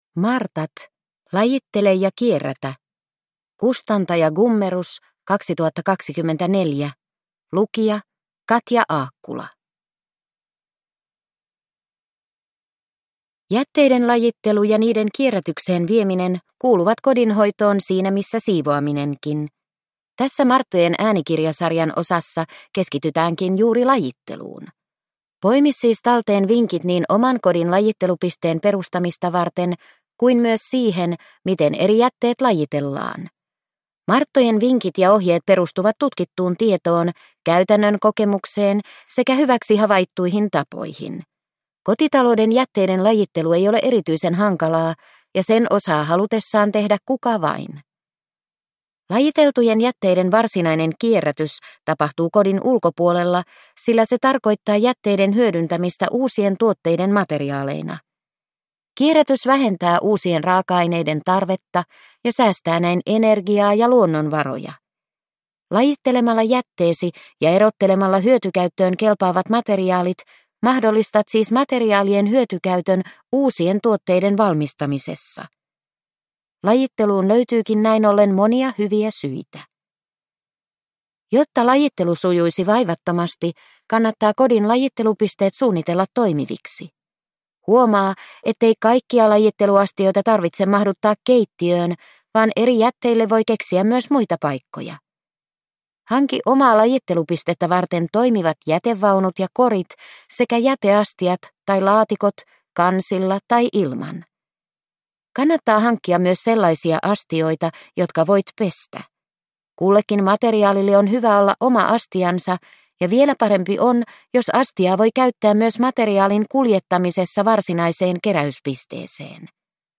Martat - Lajittele ja kierrätä – Ljudbok